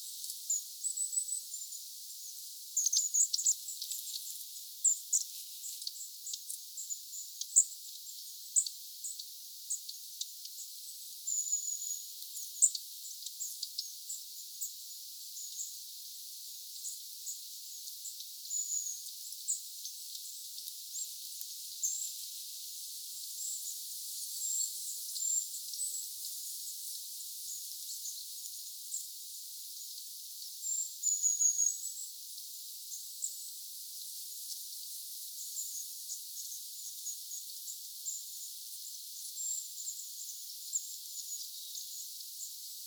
pyrstötiaisia lähellä
pyrstotiaisia_lahella.mp3